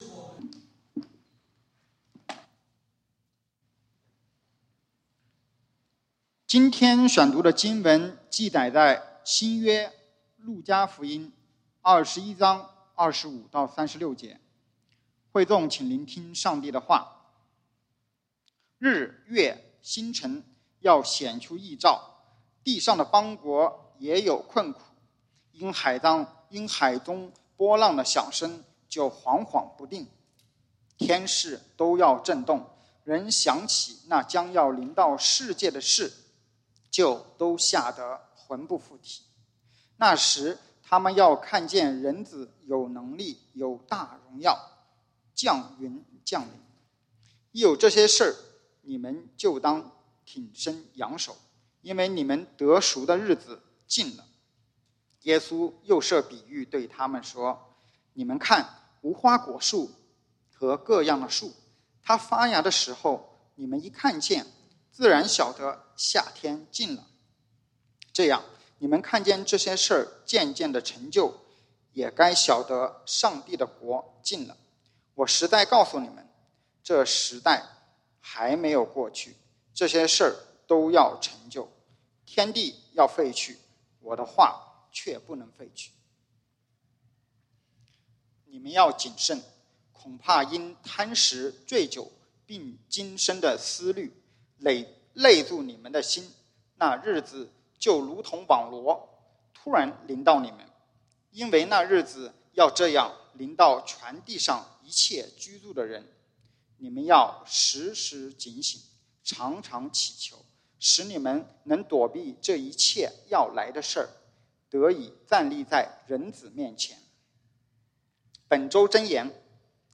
講道經文：《路加福音》Luke 21:25-36 本週箴言：《羅馬書》Romans 13:11-12 你們曉得，現今就是該趁早睡醒的時候；因為我們得救，現今比初信的時候更近了。